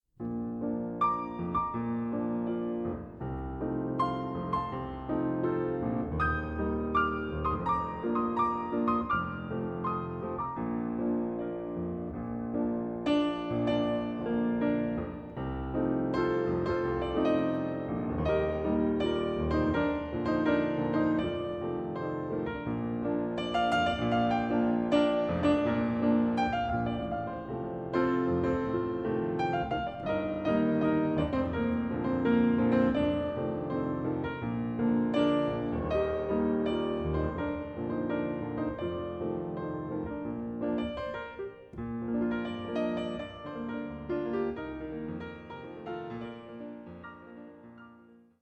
pour out a gush of cascading love and passion